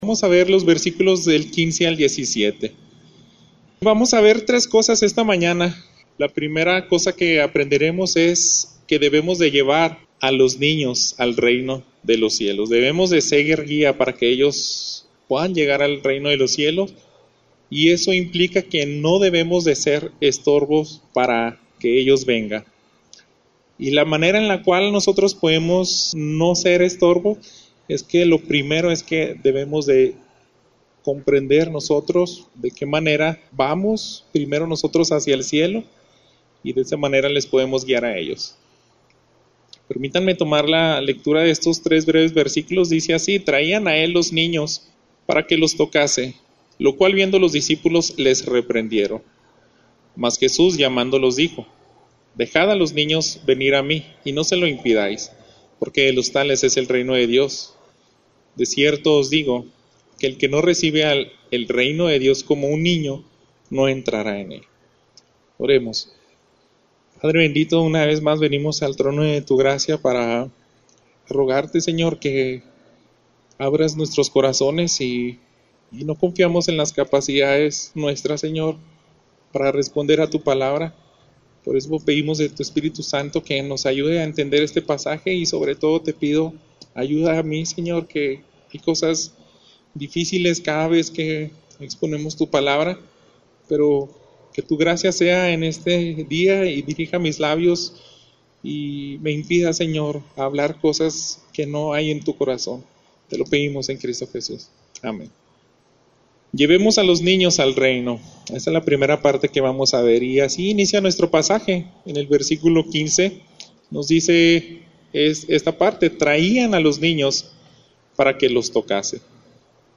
Lucas 18:15-17 En este sermón vemos un llamado a traer los niños a Jesús, no impedirselos y hacerlo con el ejemplo